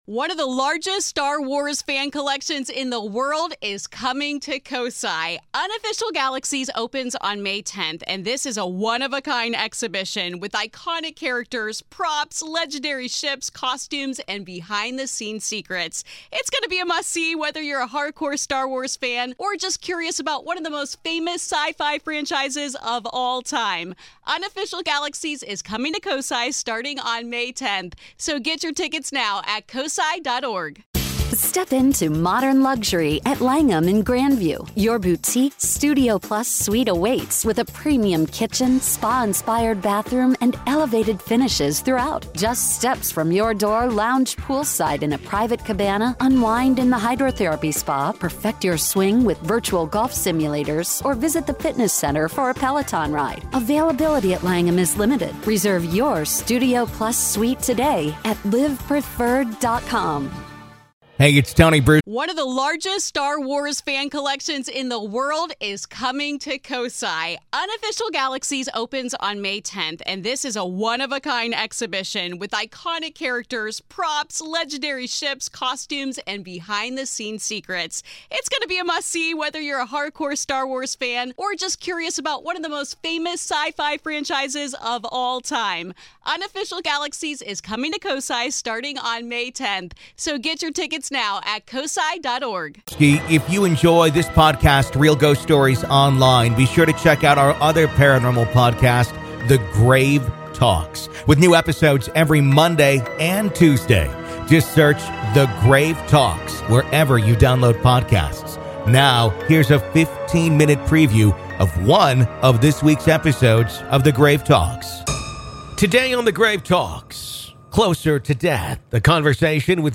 Closer To Death | A Conversation